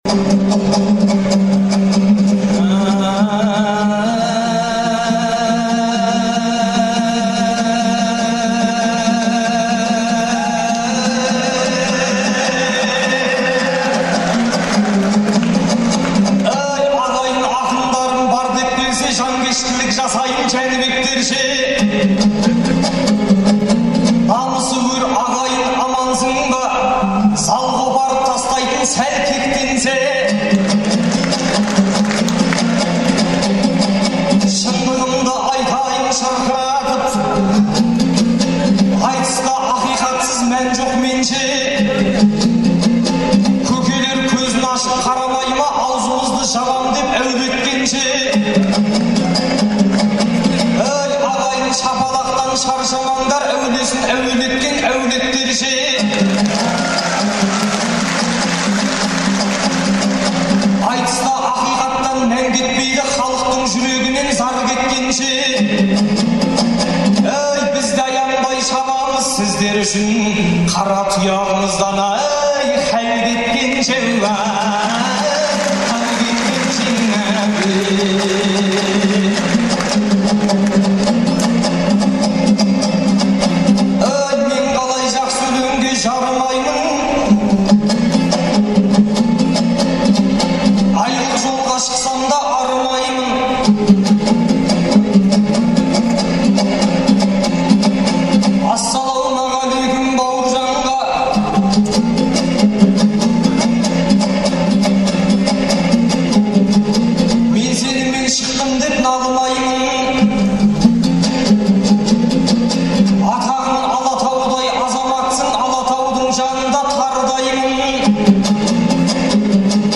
Алматыдағы Балуан Шолақ атындағы спорт сарайында ақпанның 11-і мен 12-сі күні «Қонаевтай ер қайда» деген атпен айтыс өтті.